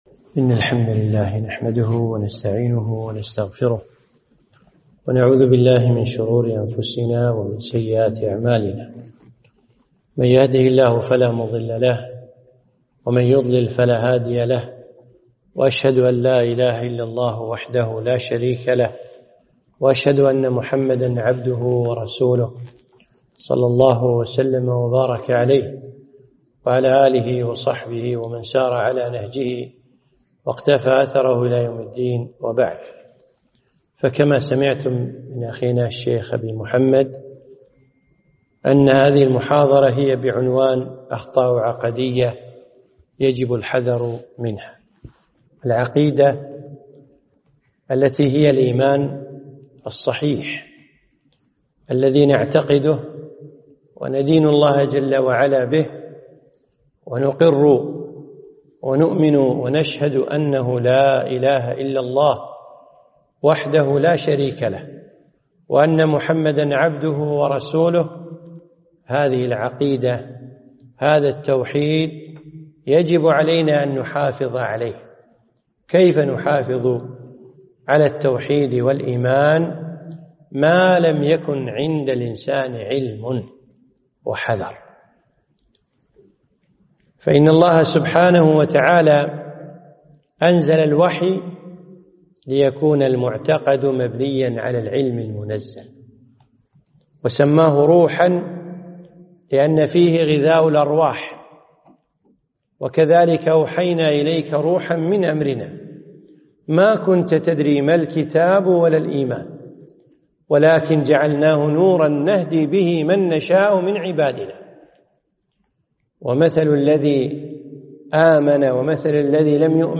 محاضرة - أخطاء عقدية يجب الحذر منها